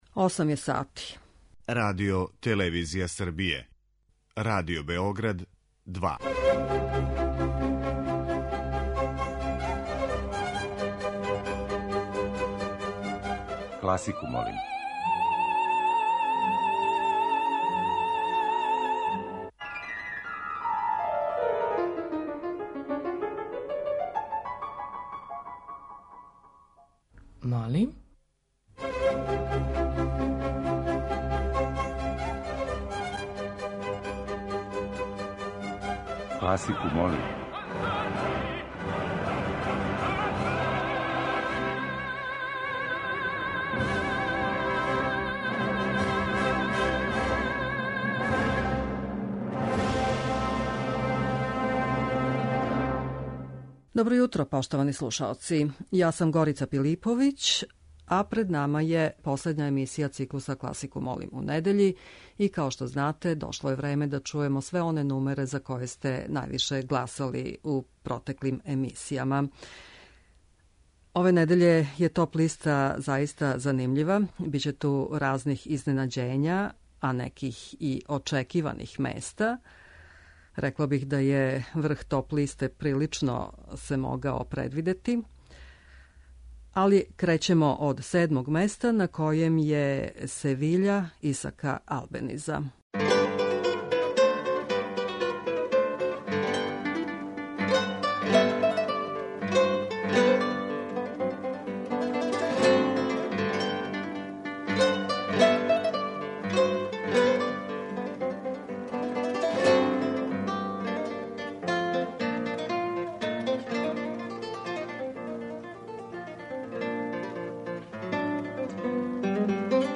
Избор за недељну топ-листу класичне музике Радио Београда 2